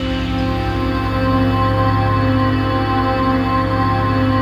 ATMOPAD19 -LR.wav